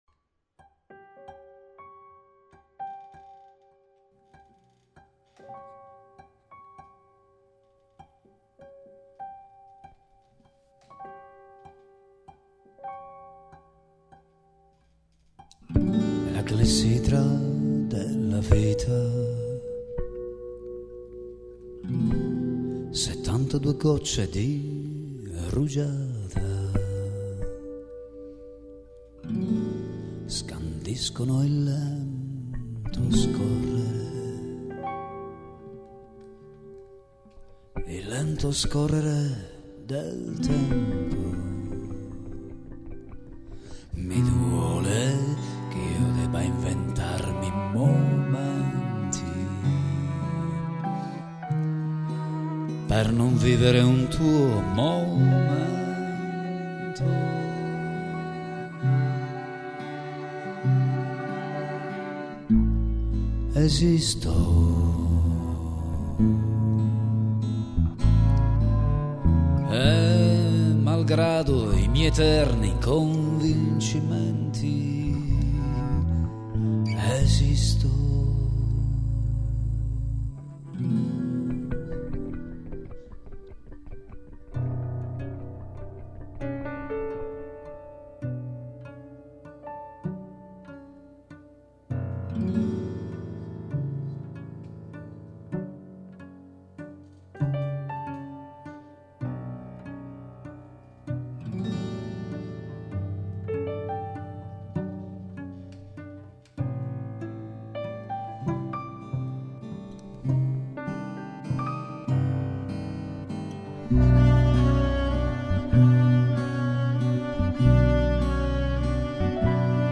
Chitarra
Archi
Pianoforte
Basso elettrico